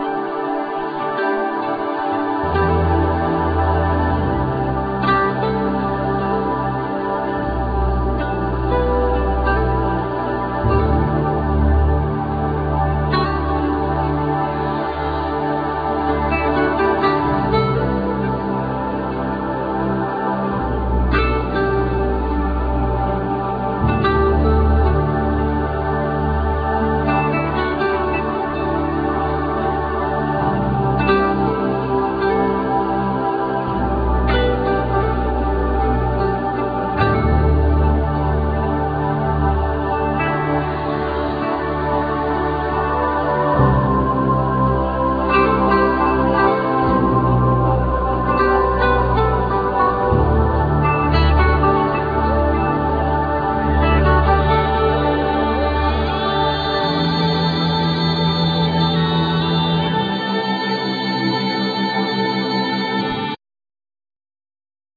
Vocal,Violin
Bagpipe,Accordion,Vocals
Kokle,Keyboards,Kalimba,China flute,Vocals
Acoustic & Electric guitars
Fretless,Acoustic & Double bass